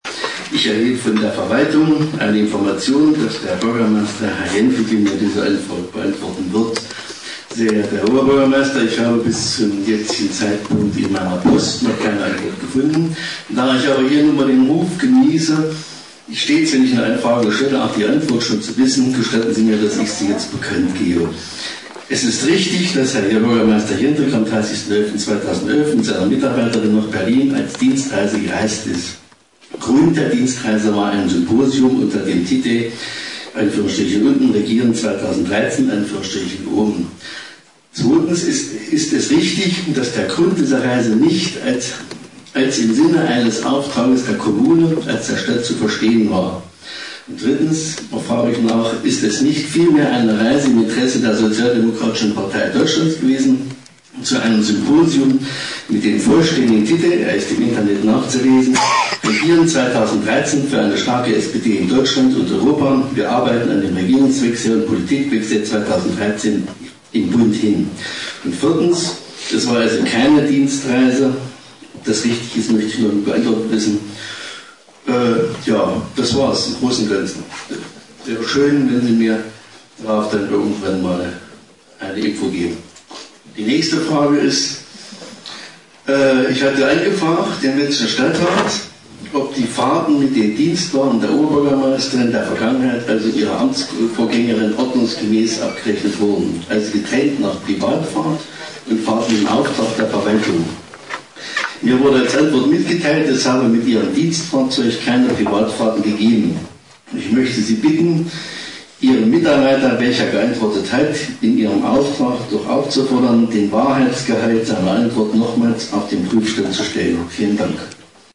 Nun aber fragte Martin Höfer gestern im Stadtrat nicht nur nach, sondern - gab auch die Antwort auf seine Frage.